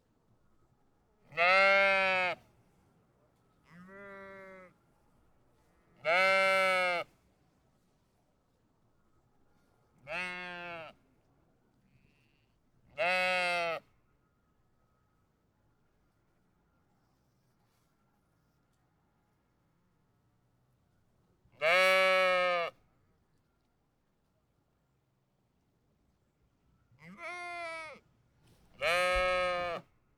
animals